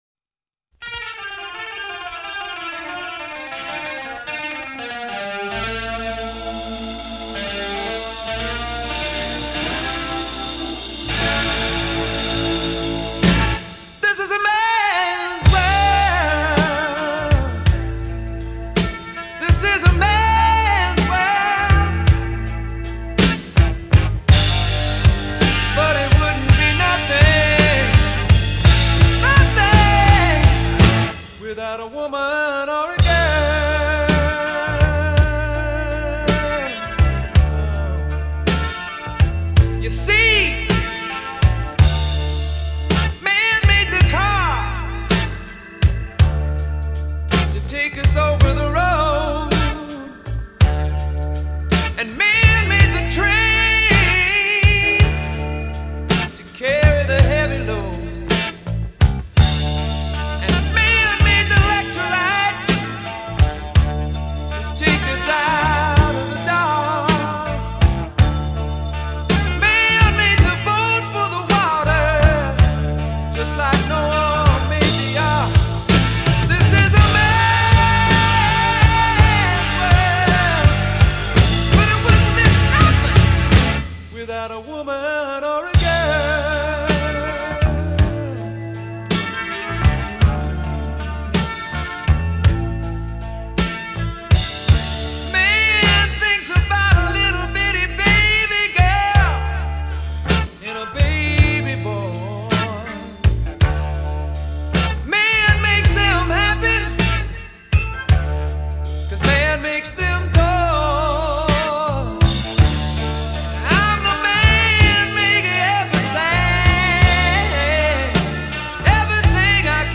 Жанры: Хард-рок, Блюз-рок